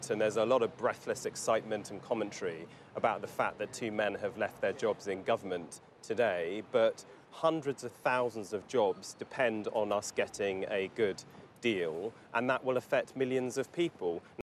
Chuka Umunna speaking about Dominic Raab